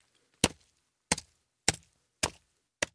На этой странице представлены звуки мин – от глухих подземных взрывов до резких срабатываний нажимных механизмов.
Звук разбросанных частей тела человека